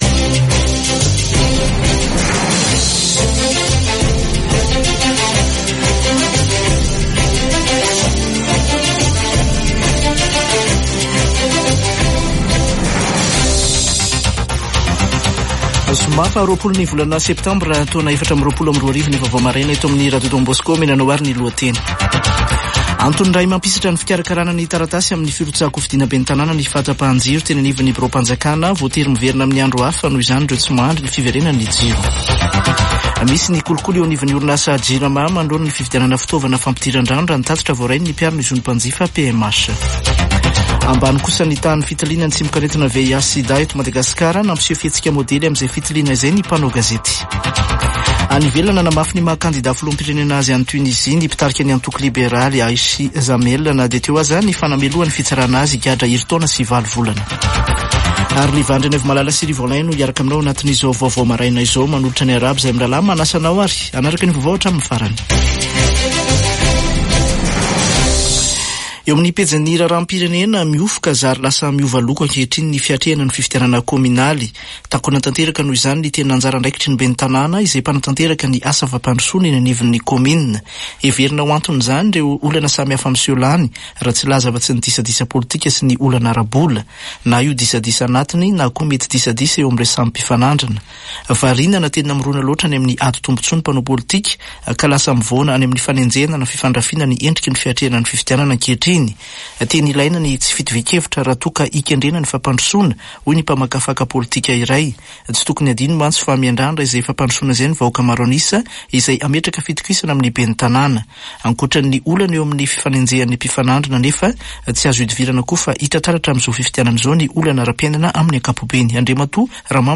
[Vaovao maraina] Zoma 20 septambra 2024